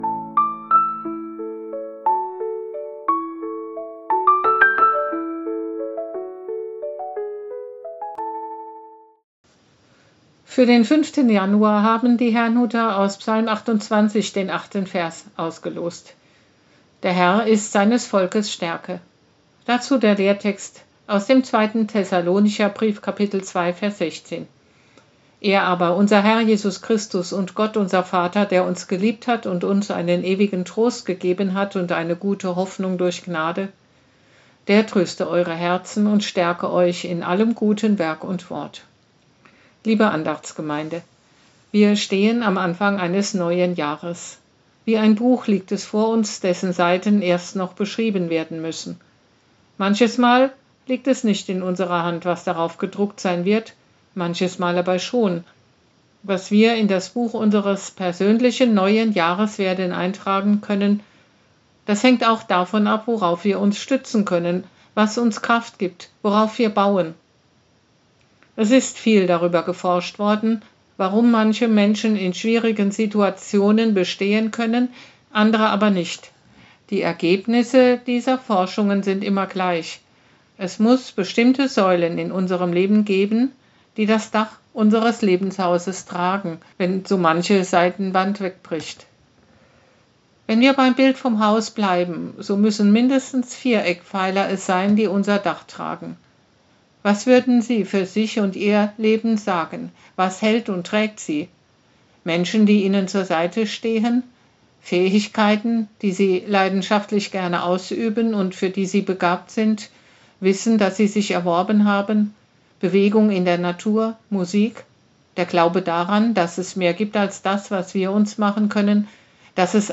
Losungsandacht für Montag, 05.01.2026 – Prot.